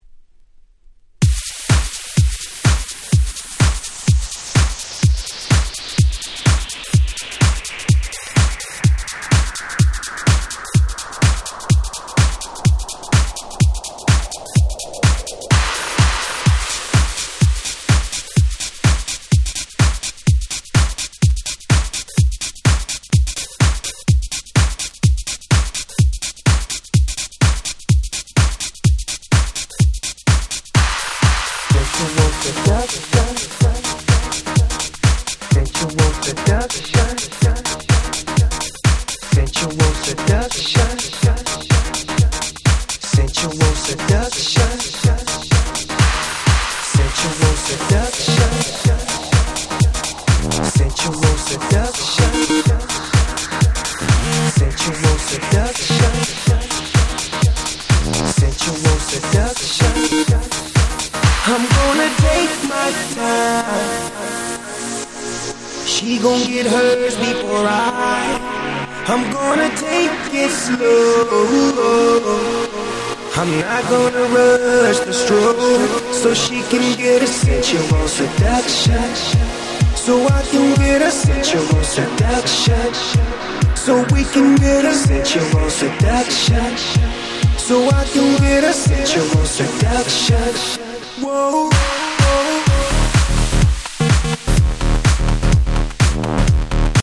07' Super Hit Hip Hop !!